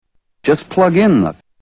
Tags: The Clapper The Clapper clips The Clapper sounds The Clapper ad The Clapper commercial